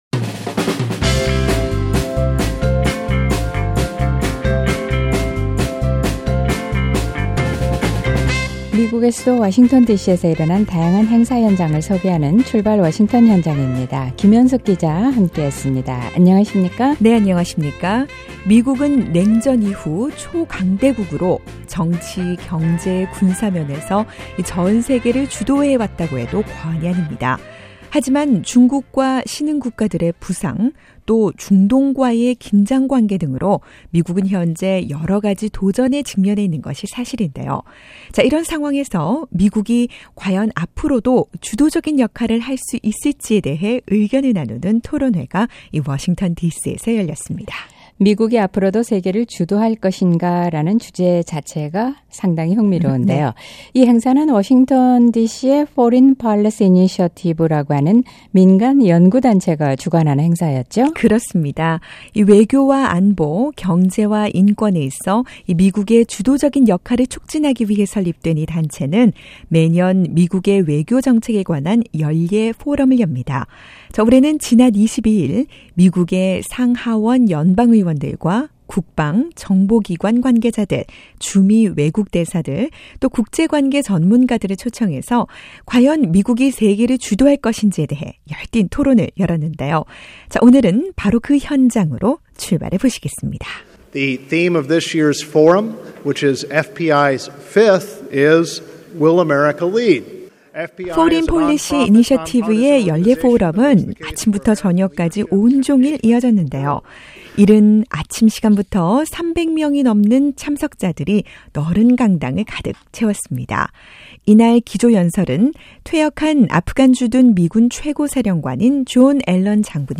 오늘은 바로 이 흥미로운 주제의 토론회 현장으로 출발해 보겠습니다.